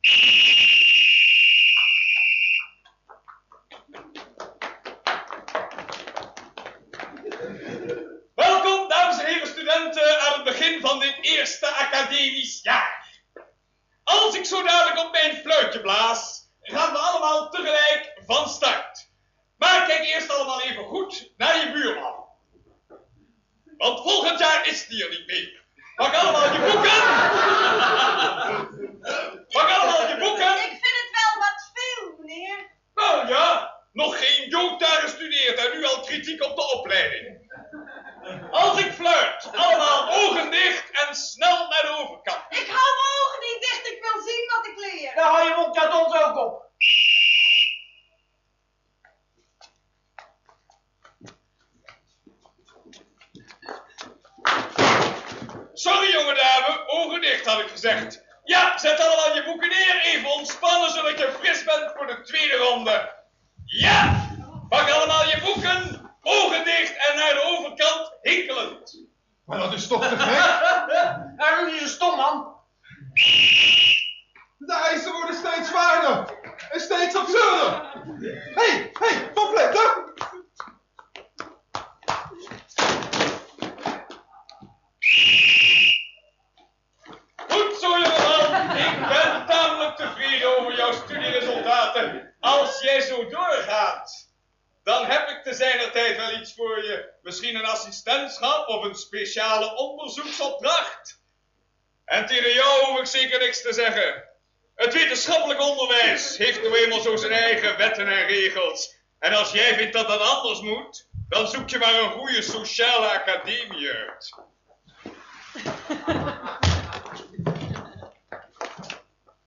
Opname tijdens een Try Out met een cassetterecorder, matige kwaliteit.